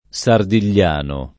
Sardigliano [ S ardil’l’ # no ]